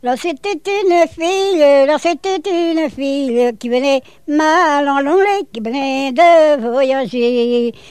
danse : branle ; danse : danse carrée ;
collecte en Vendée
Pièce musicale inédite